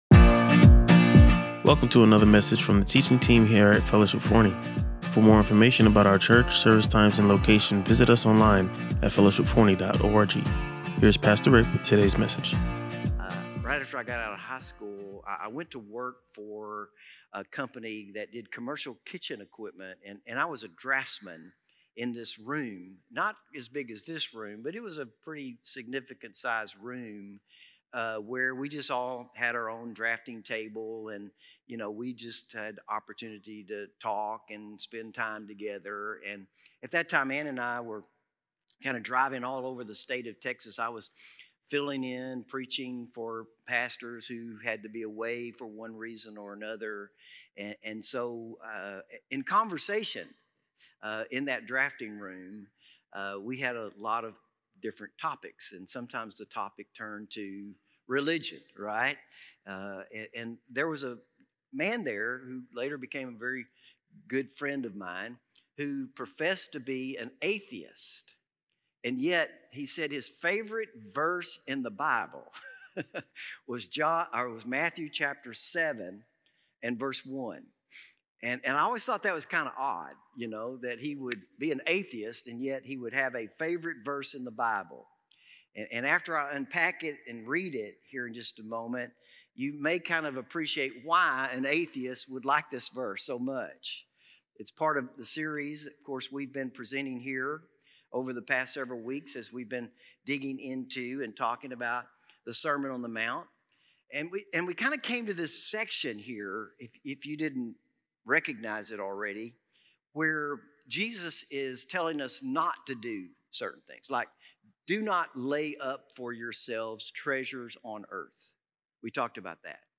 He stressed that judgment should remain within the faith community rather than being directed at non-believers, and highlighted the importance of self-examination, particularly during the Lord’s Supper. Listen to or watch the full sermon and deepen your understanding of this important biblical teaching.